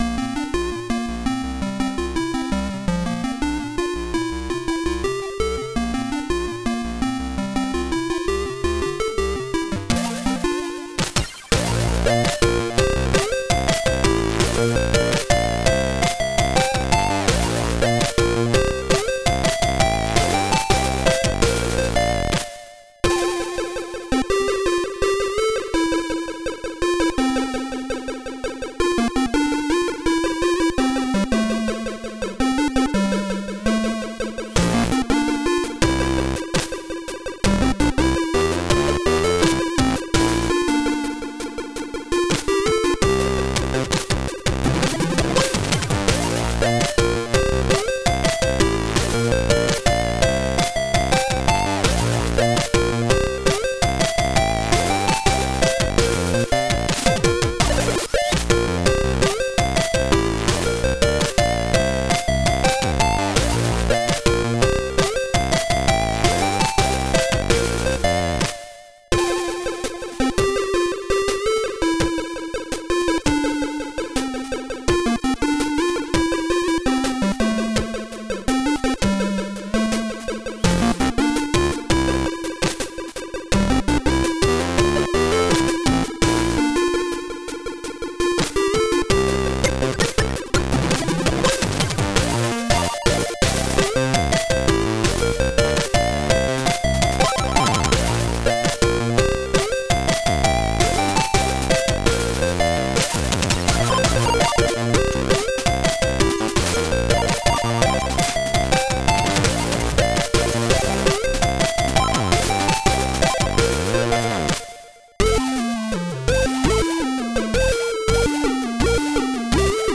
ZX Spectrum + AY
• Sound chip AY-3-8912 / YM2149